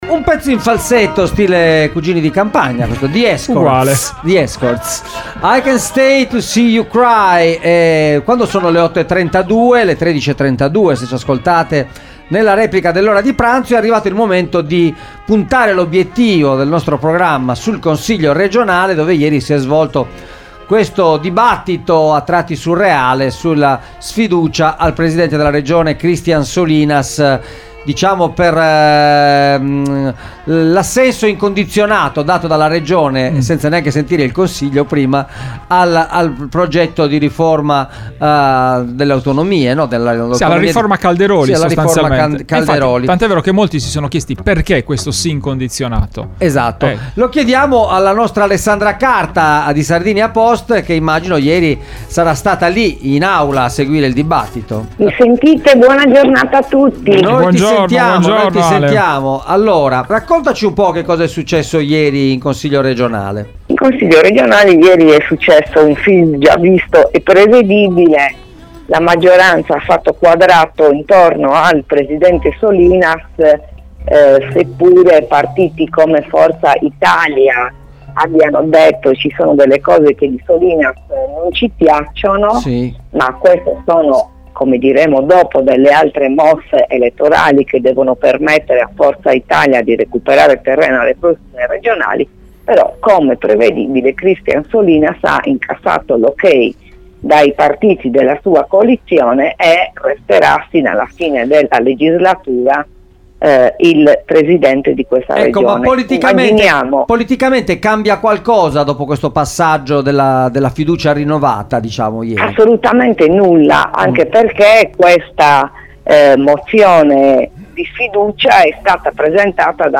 Mozione di sfiducia respinta, la maggioranza salva Solinas - Il commento